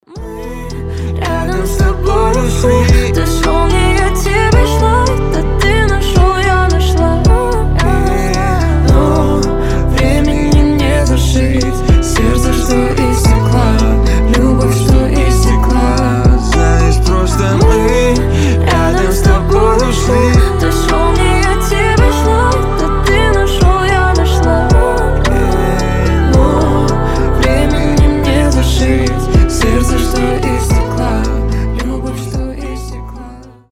• Качество: 320, Stereo
спокойные
дуэт
медленные
лирические